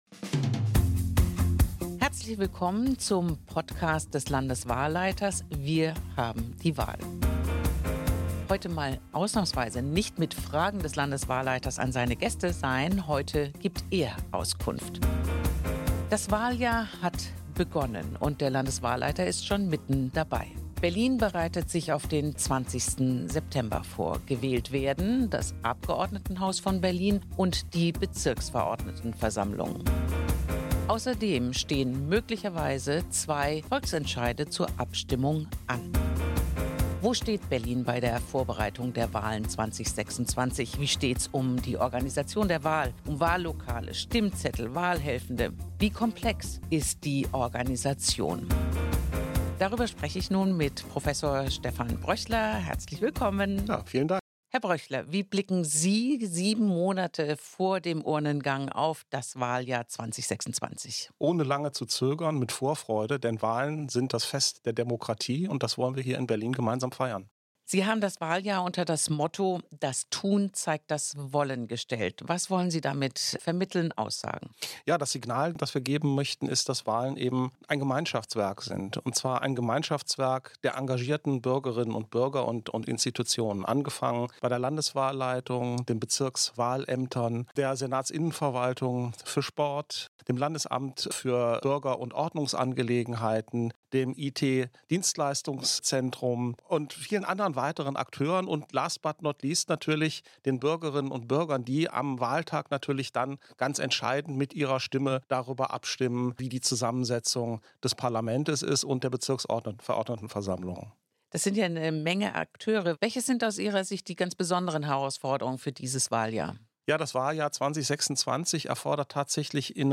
In Folge 12 seines Podcast „Wir haben die Wahl“ gibt der Landeswahlleiter für Berlin, Prof. Dr. Stephan Bröchler, einen aktuellen Einblick in die organisatorischen Vorbereitungen und benennt die Herausforderungen des Wahljahres und darüber hinaus.